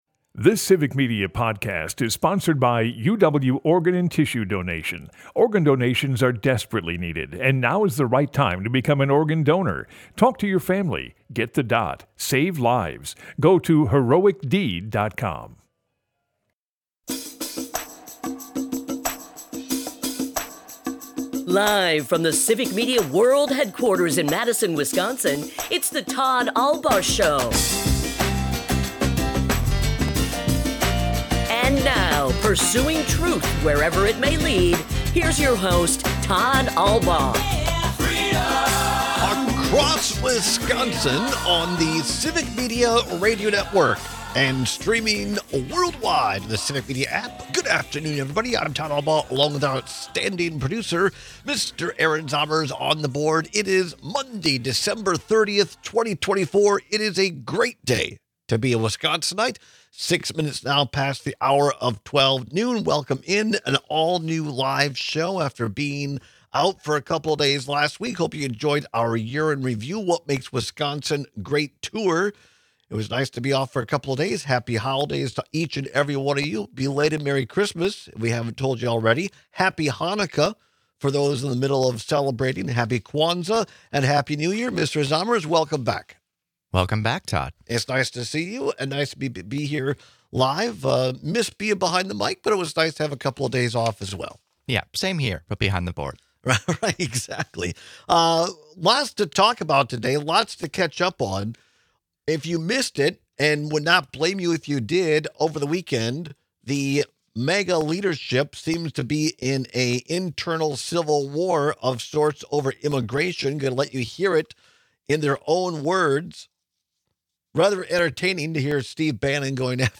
Today, we celebrate the lives and legacy of former President Jimmy Carter and State Democratic Majority Leader Tim Cullen, among others. In remembering Tim Cullen, we bring some audio from our final interview with him from September.